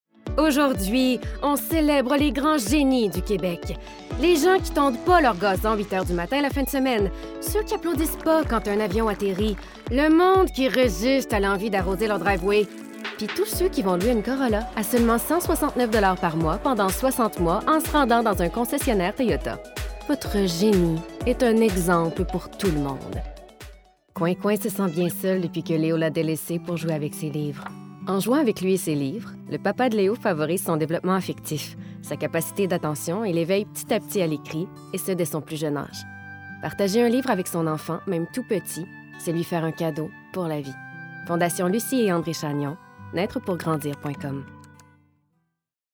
Publicity - Démo voix complet Voice-Over - Demo